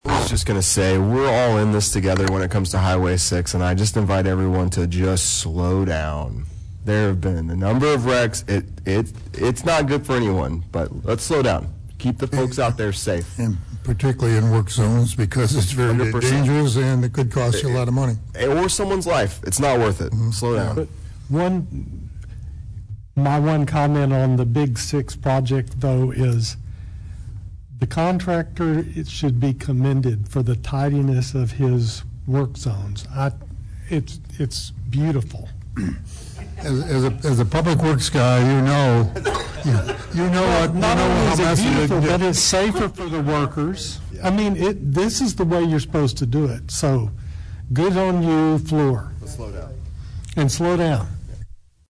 The end of Thursday night’s (January 22) nearly four hour long College Station city council meeting included observations about the Big 6 project, where construction to expand Rudder Freeway started December 1st.
Councilman William Wright initiated the conversation about multiple crashes and called on drivers to slow down.
Agreeing with Wright was mayor John Nichols and councilman Mark Smith.